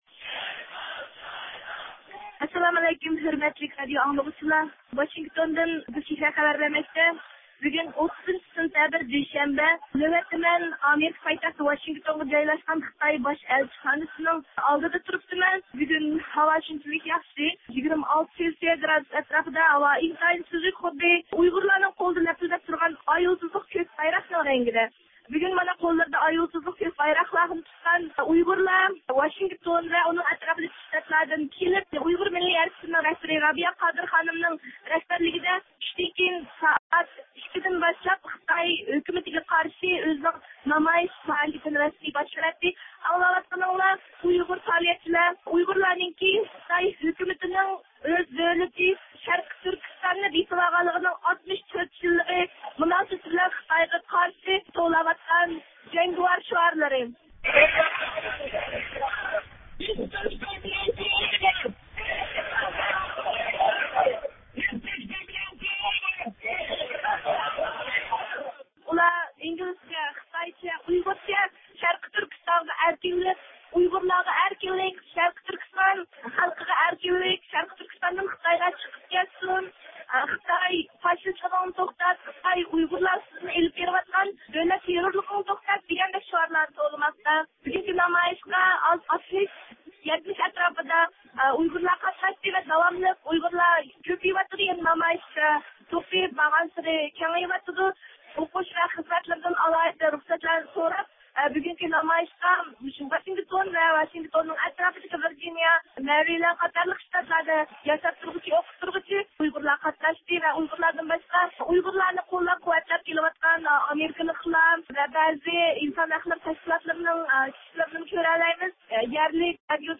نامايىش نەق مەيدانىدىن خەۋەر بېرىدۇ.